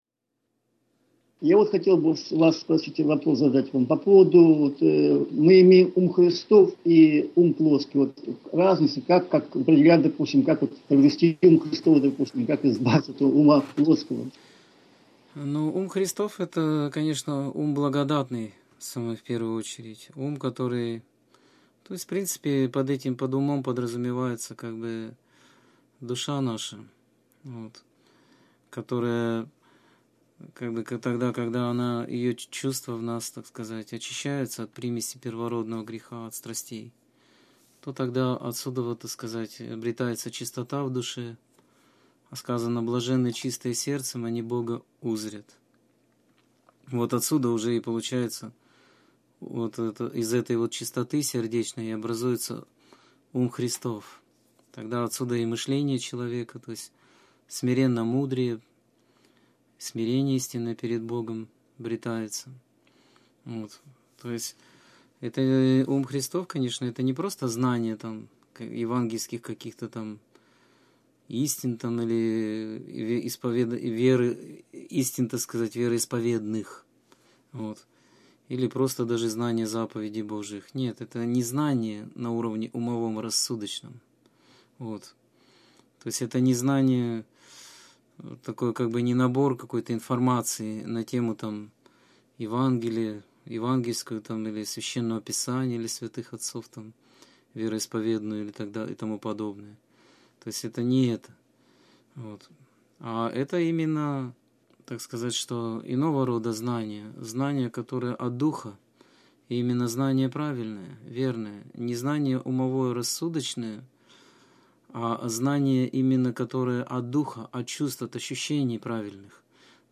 Скайп-беседа 3.09.2016